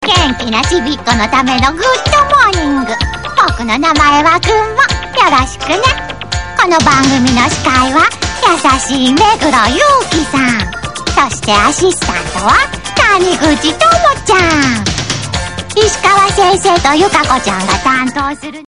ぐっともーにんぐ スイカみたいなへんてこなキャラだったので、
『ドラえもん』みたいな喋りにしてみました。